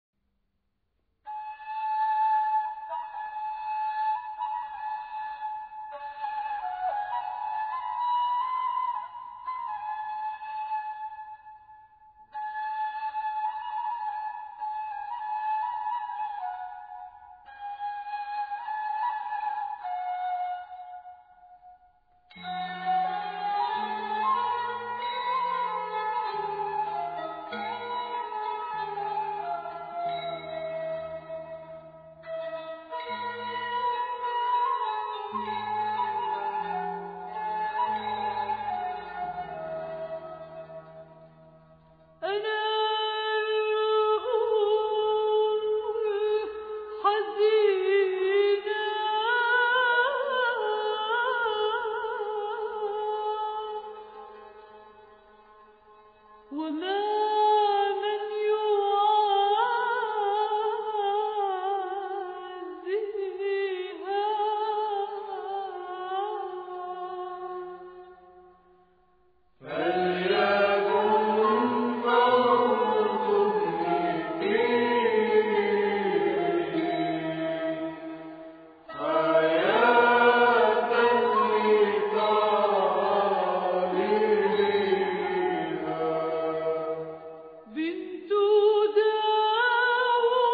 Chant traditionnel maronite